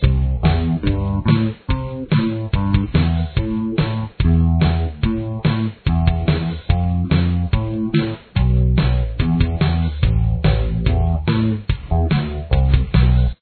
Tempo: 143 beats per minute
Key Signature: A minor
Bass Guitar